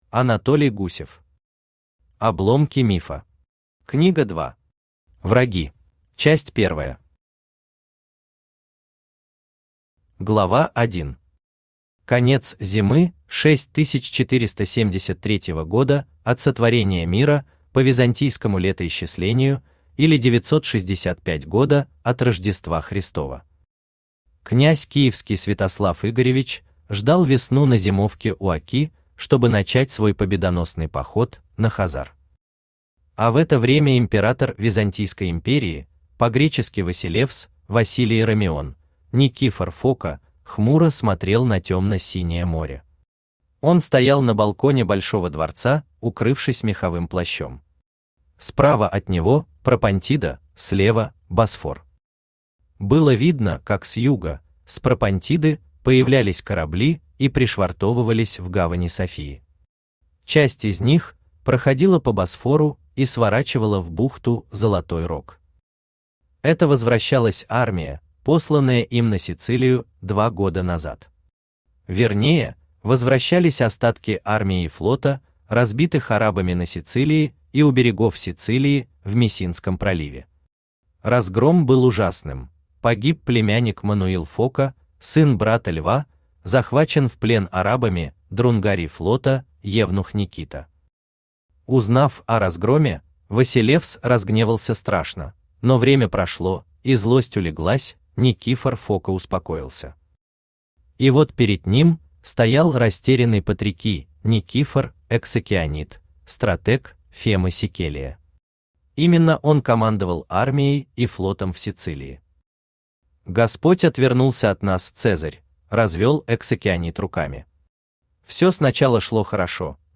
Аудиокнига Обломки мифа. Книга 2. Враги | Библиотека аудиокниг
Враги Автор Анатолий Алексеевич Гусев Читает аудиокнигу Авточтец ЛитРес.